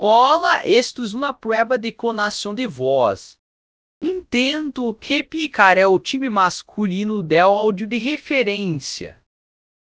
dub_male_clone_ptbr.wav